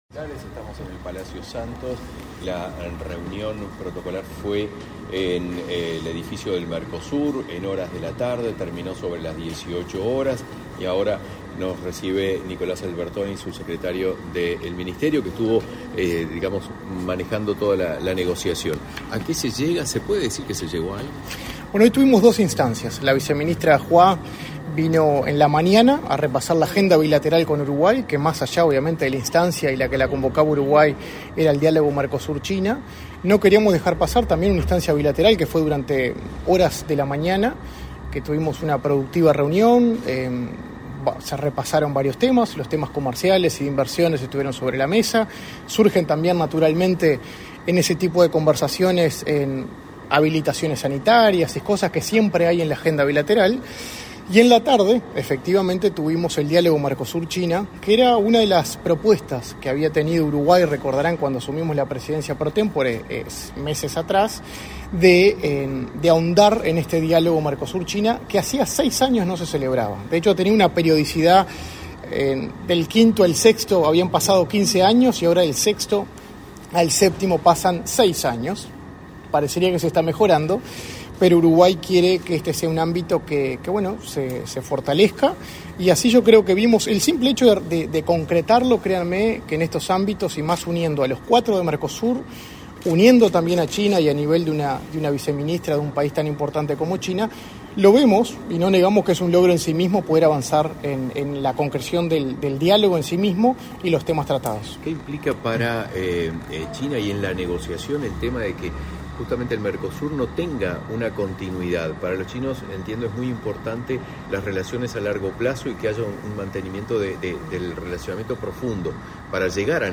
Declaraciones a la prensa del subsecretario de Cancillería, Nicolás Albertoni